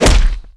空手击中3zth070522.wav
通用动作/01人物/03武术动作类/空手击中3zth070522.wav